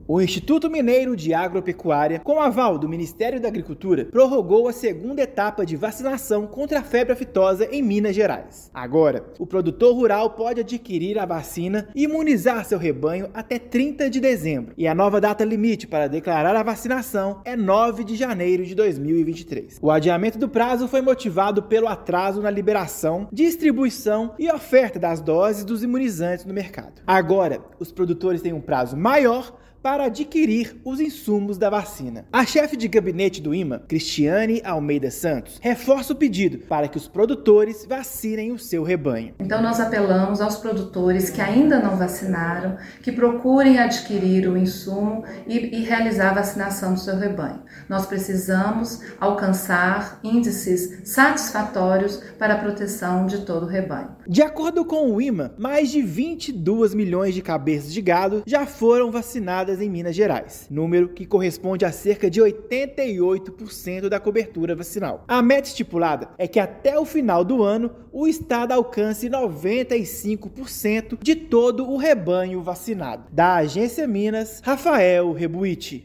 [RÁDIO] Vacinação contra febre aftosa segue até 30/12 em Minas
Nova data-limite para declarar é 9/1/2023; prorrogação atende pedido dos produtores rurais que encontram dificuldades na aquisição de doses do imunizante. Ouça matéria de rádio.